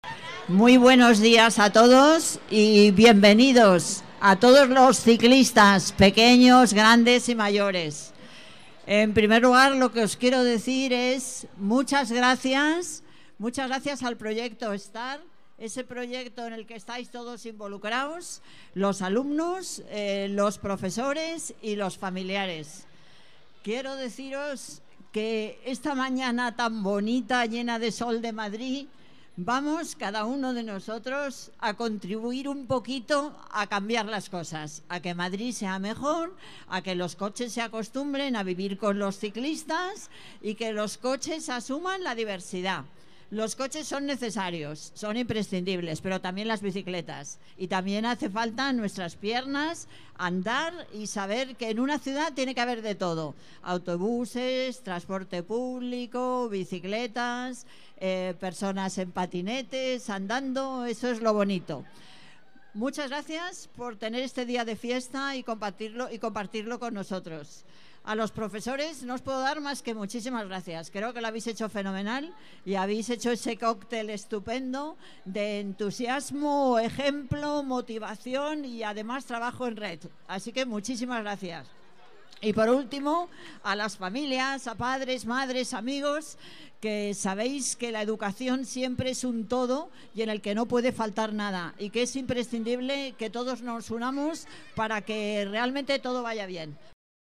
Nueva ventana:Declaraciones Manuela Carmena, alcaldesa: Paseo en bici Semana Movilidad